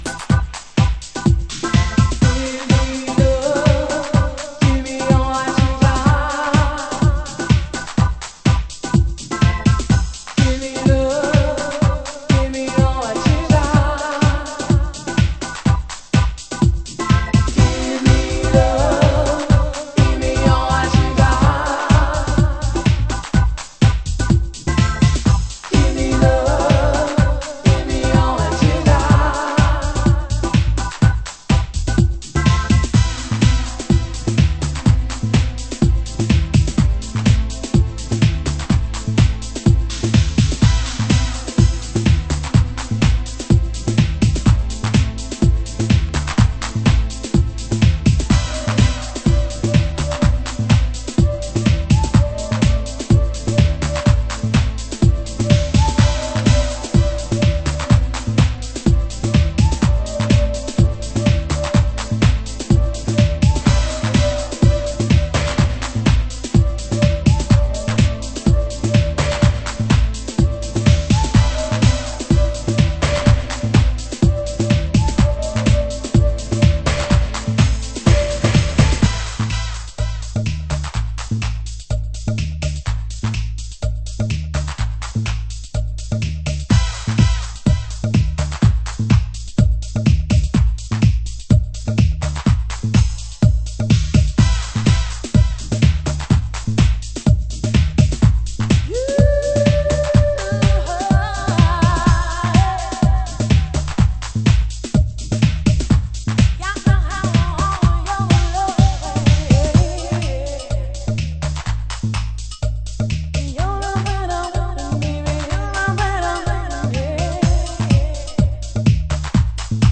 ★DEEP HOUSE 歌 WHITE
盤質：チリパチノイズ有